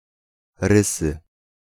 Rysy (Polish: [ˈrɨsɨ]
Pl-Rysy.ogg.mp3